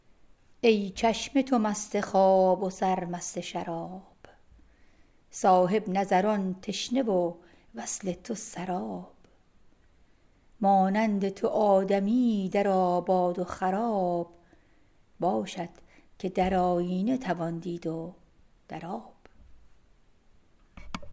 Poem recited